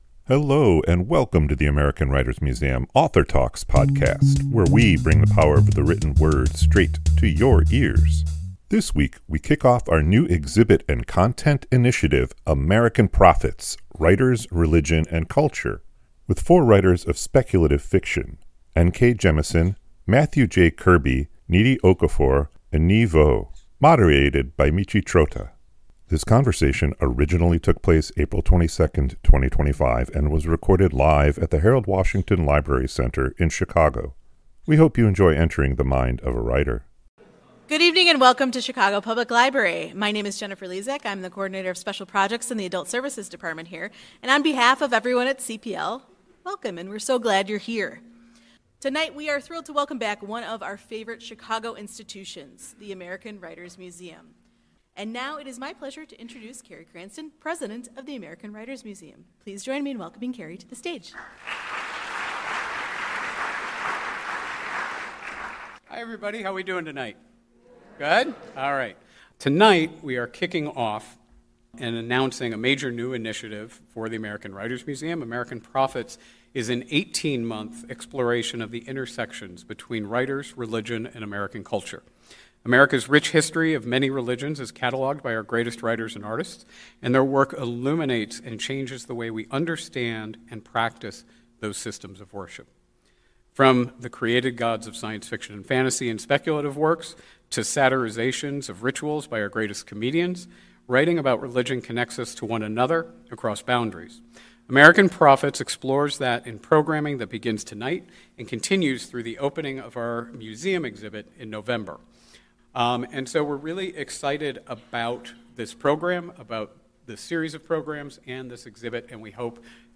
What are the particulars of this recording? This conversation originally took place April 22, 2025 and was recorded live at the Harold Washington Library Center in Chicago.